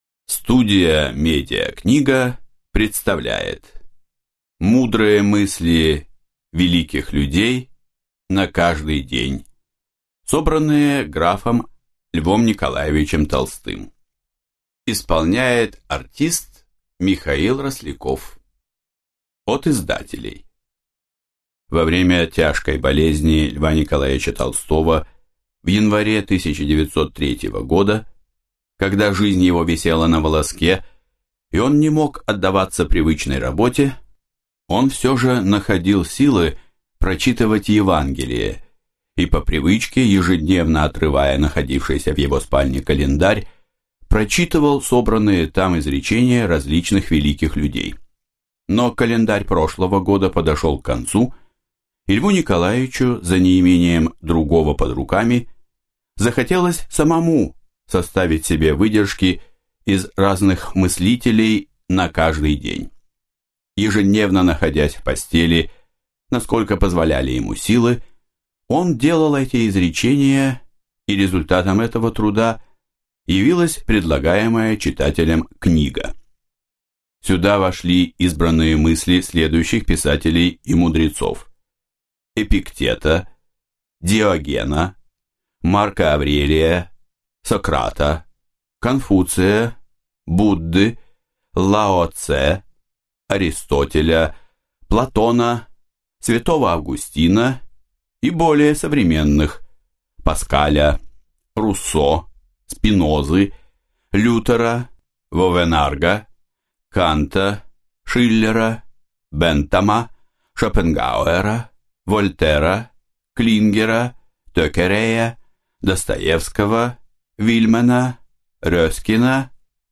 Аудиокнига Мысли мудрых людей на каждый день | Библиотека аудиокниг